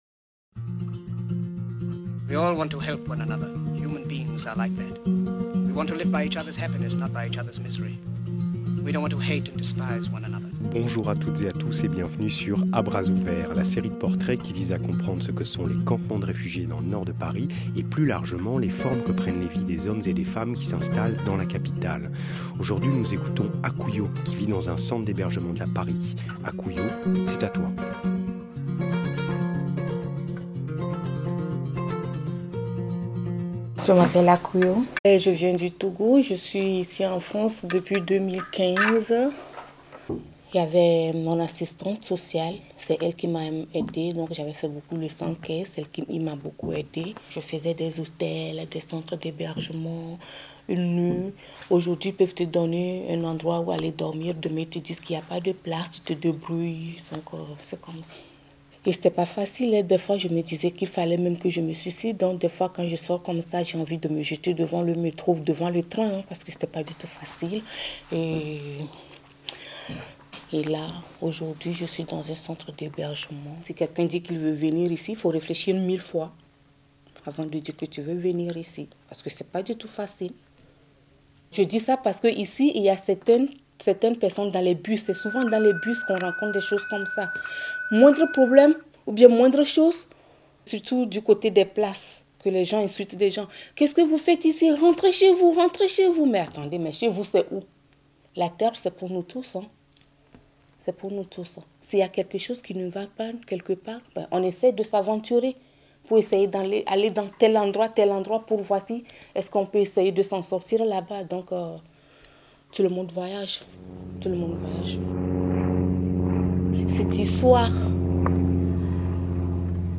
Musique : Amel Bent - Ma Philosophie